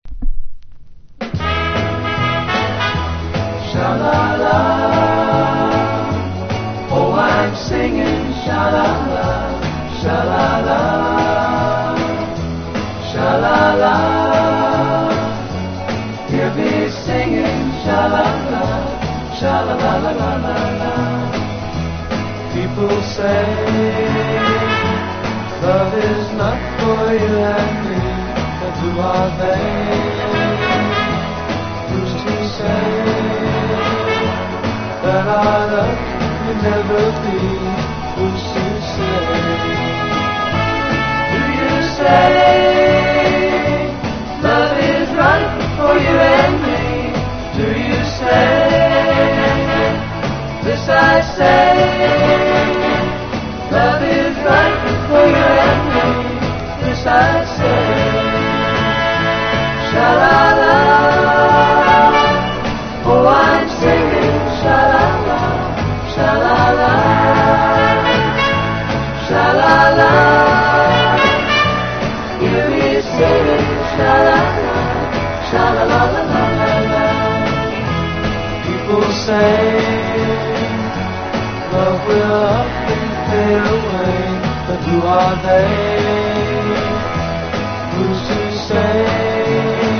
全編に渡ってサンシャイン・ポップ～ソフト・ロック・サウンドを展開。